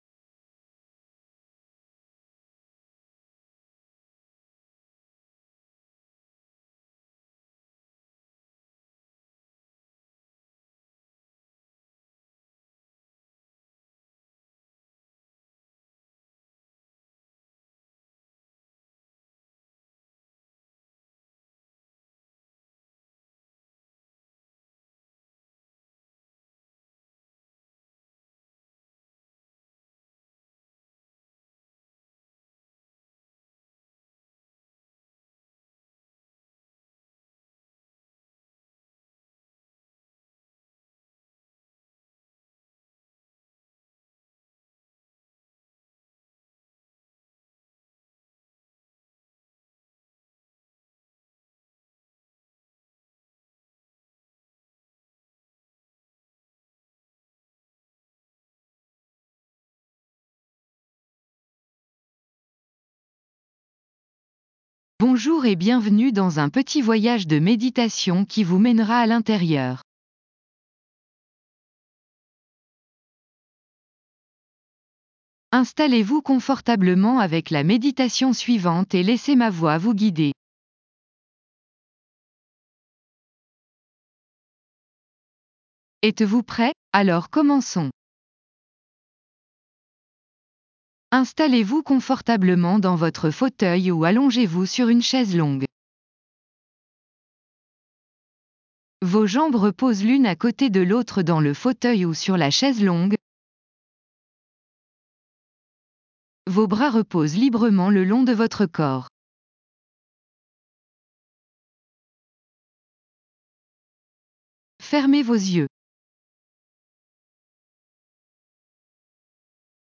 Déplacez ce 17e Chakra de 20 cm vers l'avant (ou / et 20 vers l'arrière / côtés).Maintenez votre concentration sur cet / ces endroits pendant que vous laissez la musique jouer sur vous.